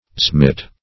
zaimet - definition of zaimet - synonyms, pronunciation, spelling from Free Dictionary
Search Result for " zaimet" : The Collaborative International Dictionary of English v.0.48: Zaimet \Zaim"et\ (?; 277), n. [Turk.